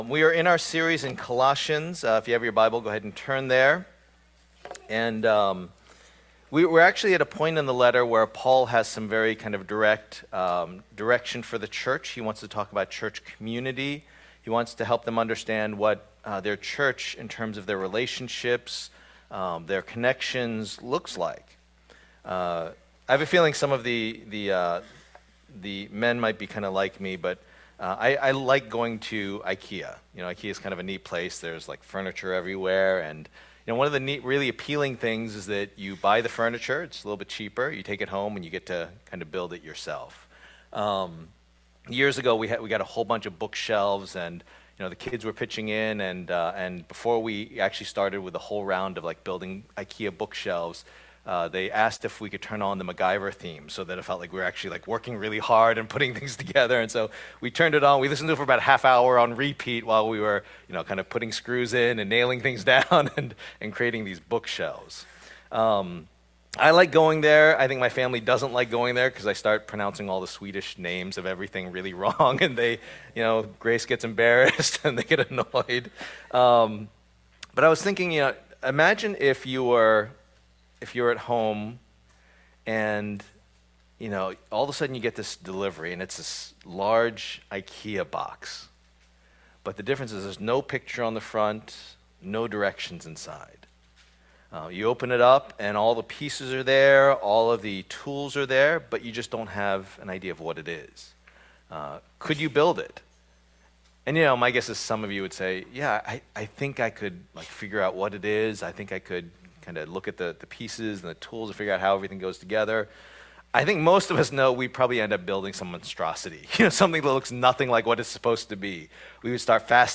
Passage: Colossians 1:24-2:5 Service Type: Lord's Day